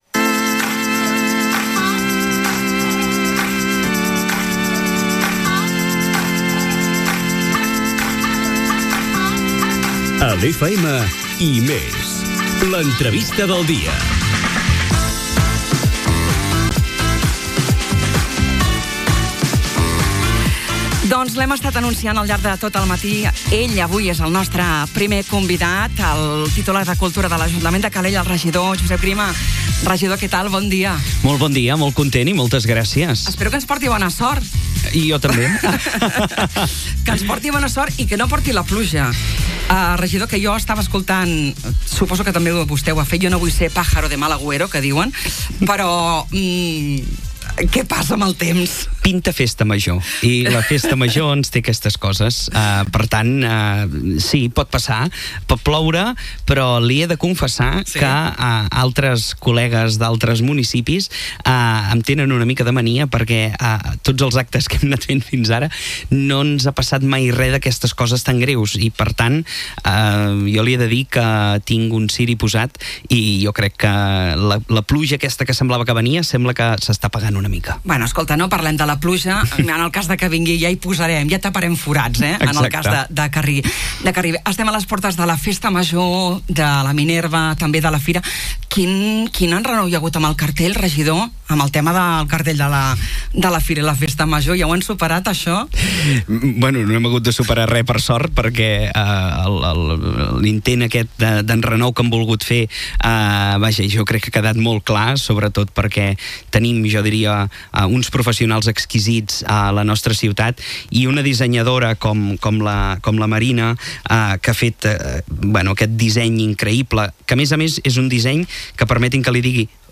Entrevista Josep Grima, tinent d'alcaldia de Cultura i Fires
Avui a les 11, passarà pels estudis de RCT el titular de Cultura de l’Ajuntament de Calella, Josep Grima.